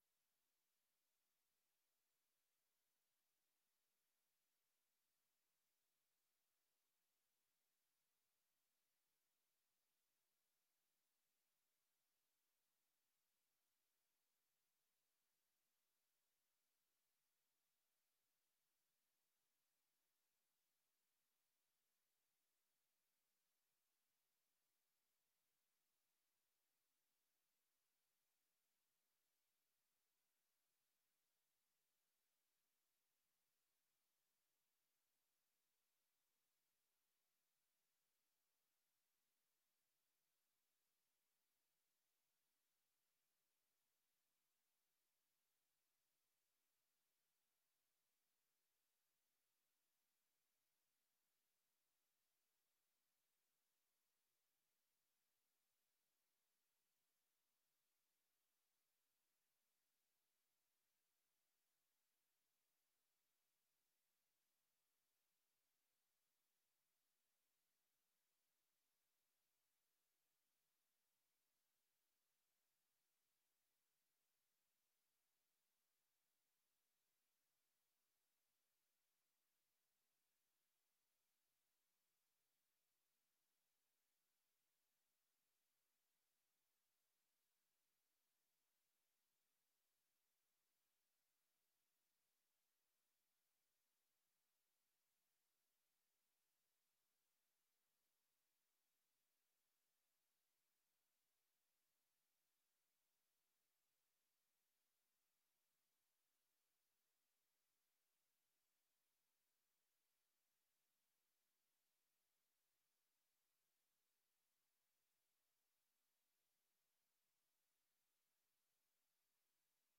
Vergadering : Gemeenteraad
Locatie: Raadzaal